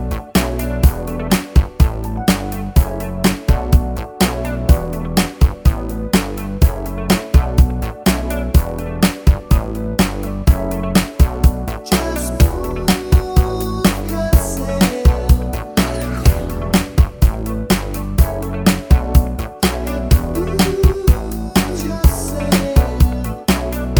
no Backing Vocals with vocoder Disco 4:11 Buy £1.50